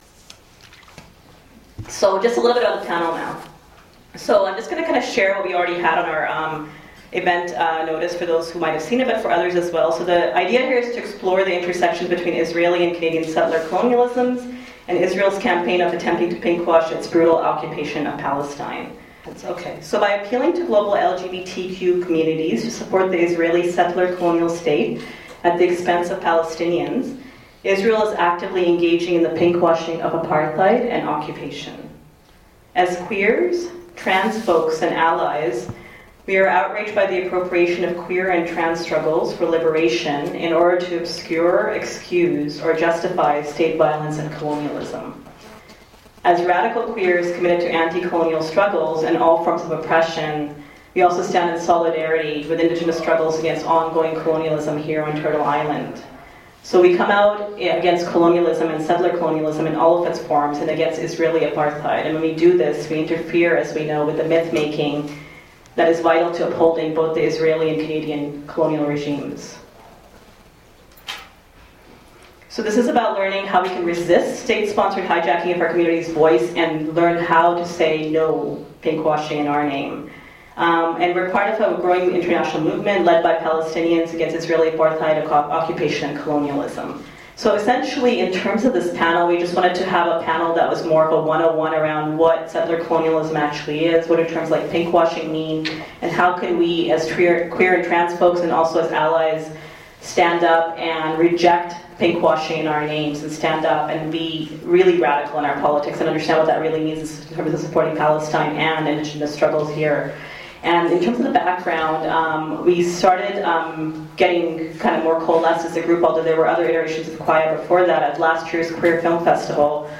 Panel on Settler Colonialisms, Queer Activism and Pinkwashing of Israeli Apartheid